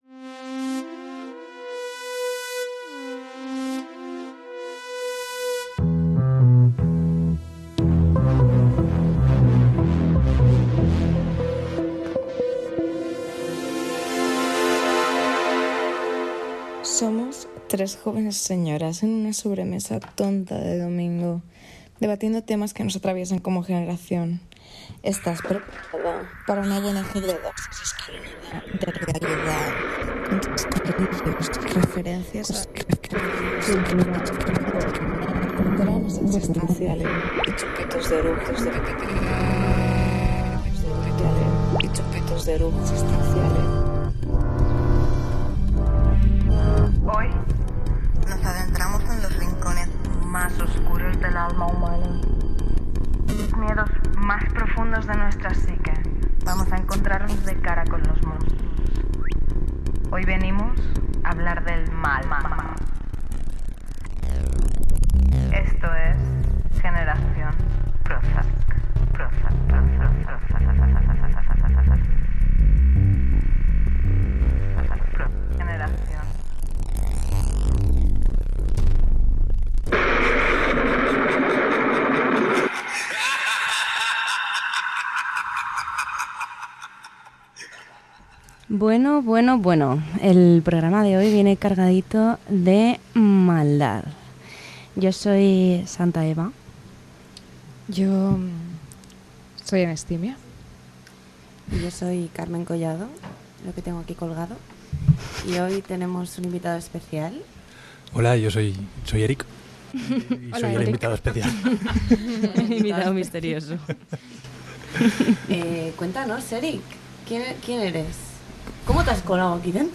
Careta del programa, presentació del programa dedicat al mal amb la participació d'un criminòleg.